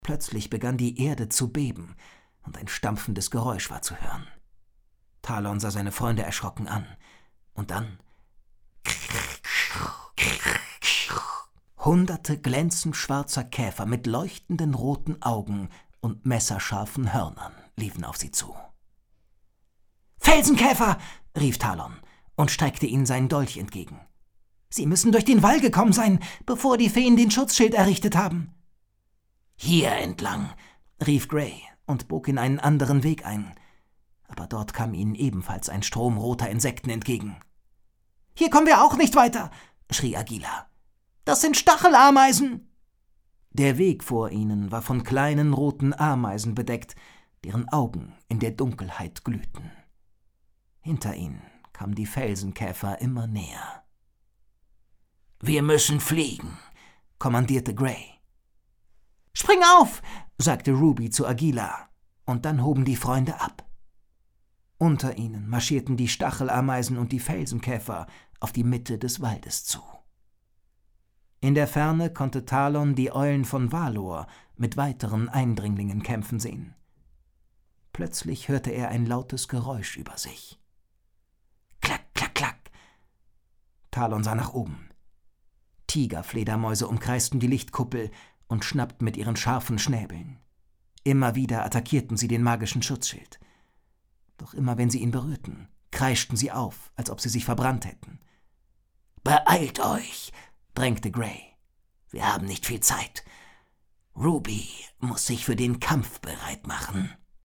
Schlagworte Abenteuergeschichten • action • Adventure • Buch • Fantasy • Freundschaft • Hörbuch • Kinderbuch • Kinderhörbuch • Magie • Mut • Perodia • Quest • sprechende Tiere